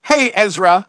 synthetic-wakewords
synthetic-wakewords / hey_ezra /ovos-tts-plugin-deepponies_Discord_en.wav